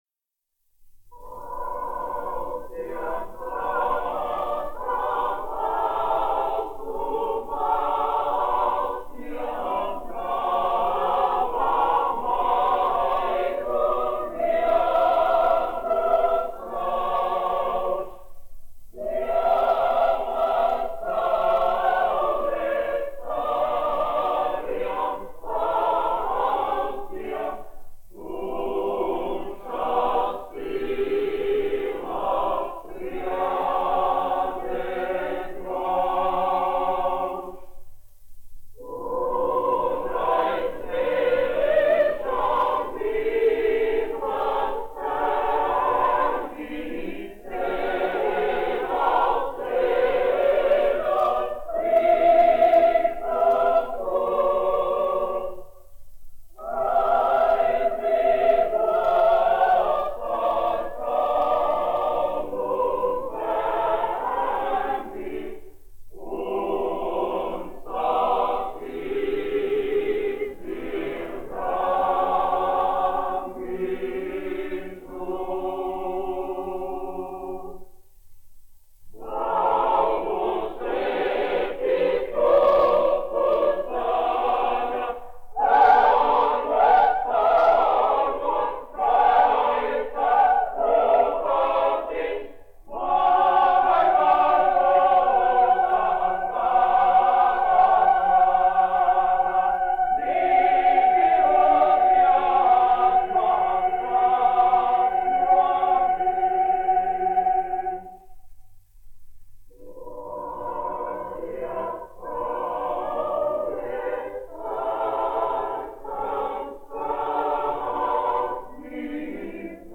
1 skpl. : analogs, 78 apgr/min, mono ; 25 cm
Kori (jauktie)
Skaņuplate